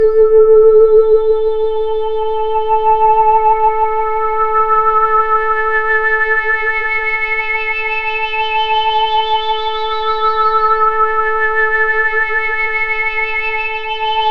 PAD REZO 0DR.wav